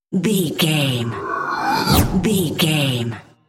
Airy whoosh pass by
Sound Effects
No
futuristic
sci fi